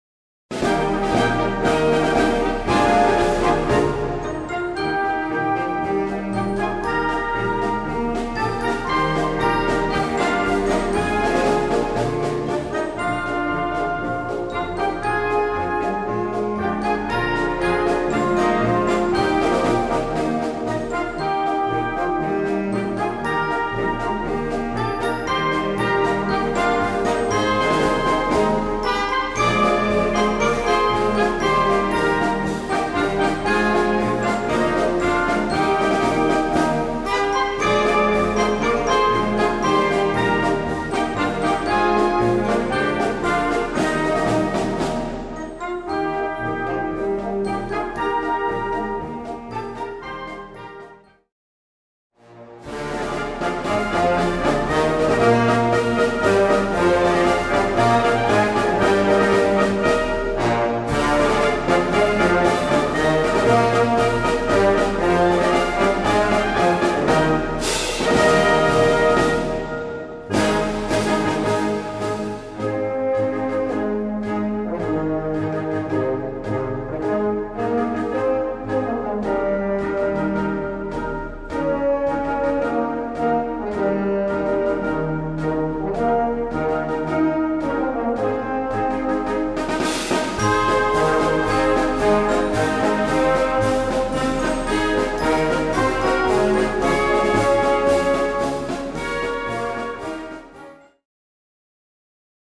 16:30 Minuten Besetzung: Blasorchester Zu hören auf